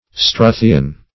struthian - definition of struthian - synonyms, pronunciation, spelling from Free Dictionary Search Result for " struthian" : The Collaborative International Dictionary of English v.0.48: Struthian \Stru"thi*an\, a. (Zool.)